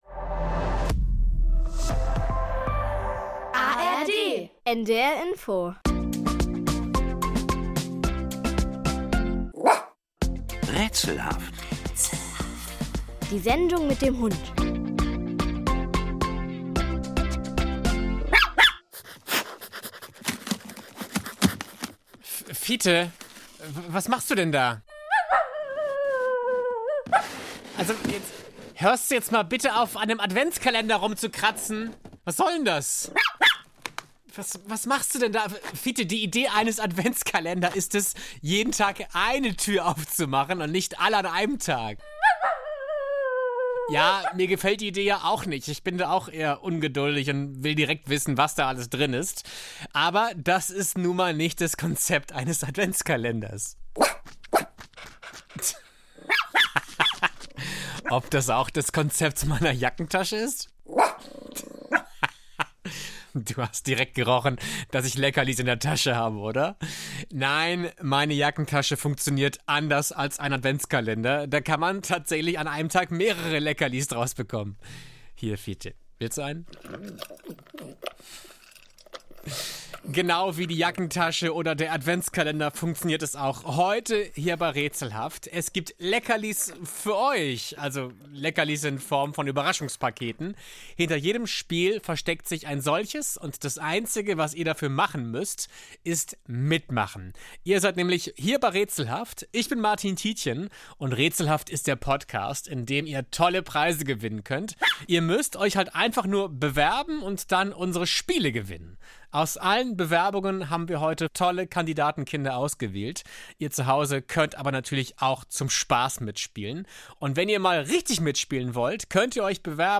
In verschiedenen Raterunden treten Kinder gegeneinander an.